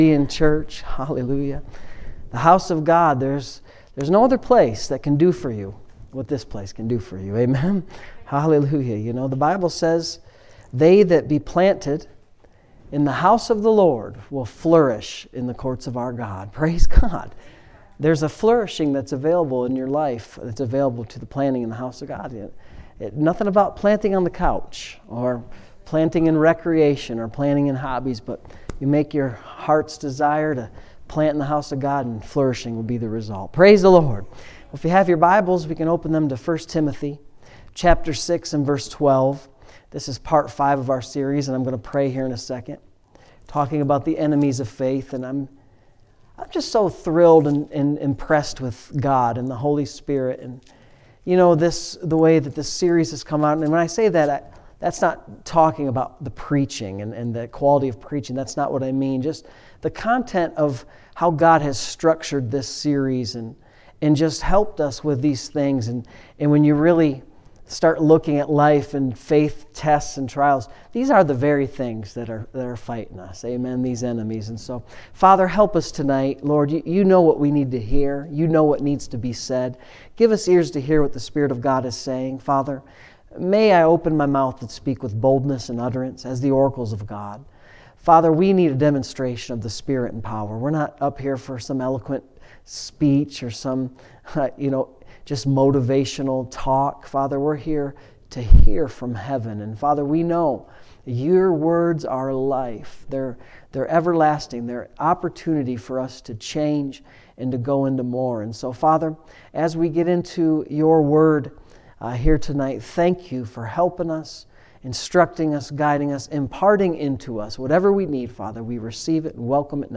Saturday Evening Services